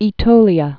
(ē-tōlē-ə, -tōlyə)